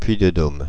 Ääntäminen
Ääntäminen Paris: IPA: [pɥi.də.dom] Haettu sana löytyi näillä lähdekielillä: ranska Käännöksiä ei löytynyt valitulle kohdekielelle.